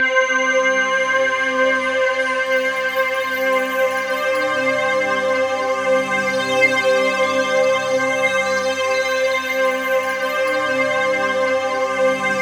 TUBULARC4.-R.wav